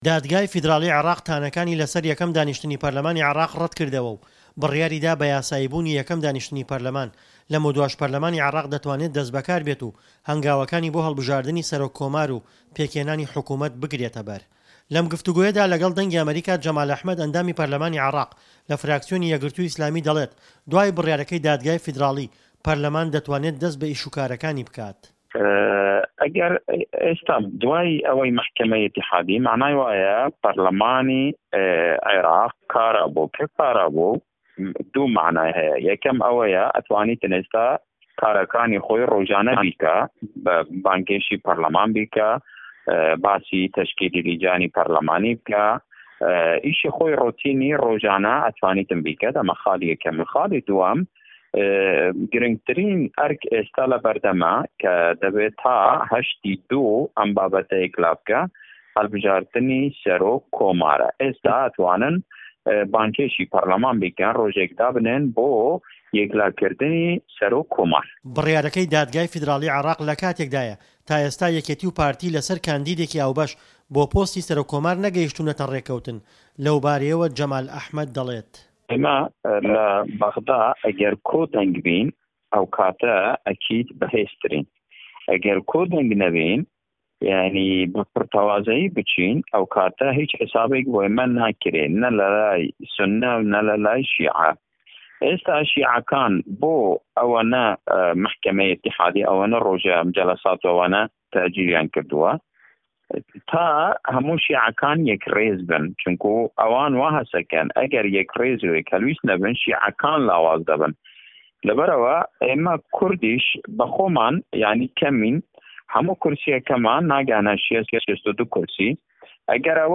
دادگای فیدراڵی عێراق تانەکانی لەسەر یەکەم دانیشتنی پەرلەمانی عێراق ڕەتکردەوە و بڕیاریدا بە یاسایی بوونی یەکەم دانیشتنی پەرلەمان، لەمەودواش پەرلەمانی عێراق دەتوانێت دەست بەکاربێت و هەنگاوەکانی بۆ هەڵبژاردنی پۆستی سەرۆک کۆمار و پێکهێنانی حکومەت بگرێتەبەر. لەم گفتووگۆیەدا لەگەڵ دەنگی ئەمەریکا،...